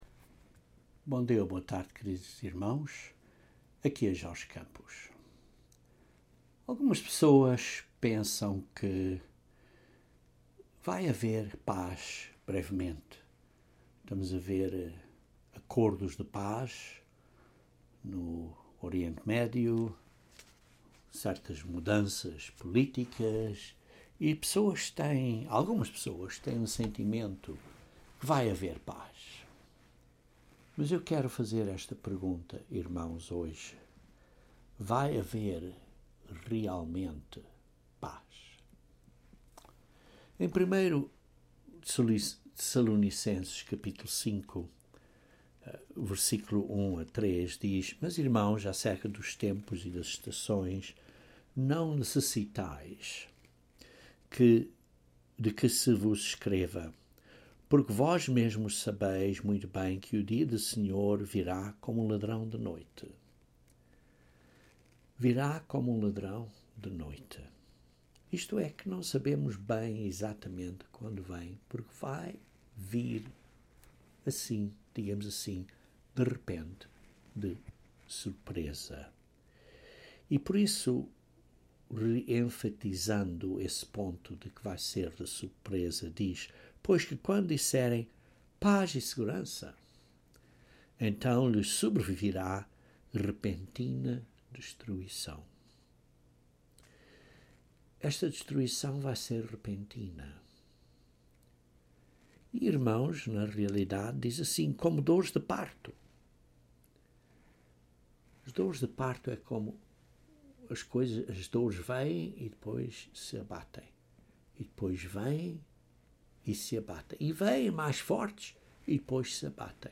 Este sermão aborda a guerra entre Satanás e Israel. Não haverá paz até Satanás ser expulso do céu.